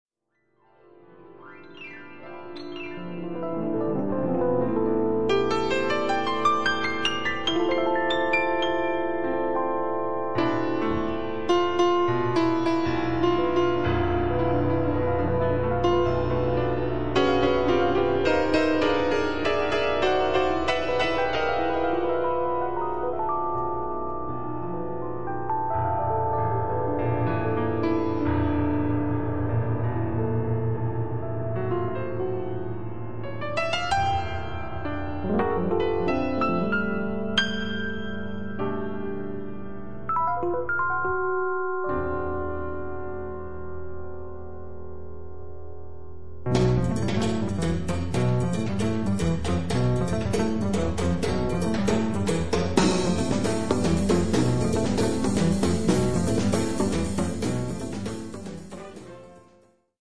piano, Fender Rhodes
tromba, flicorno
Percussioni